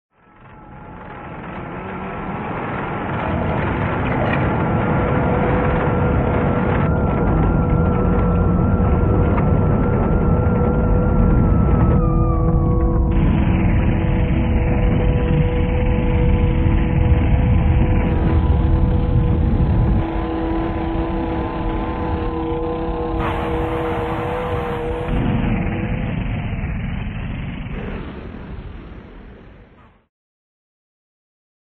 Segment Jazz-Rock
Progressive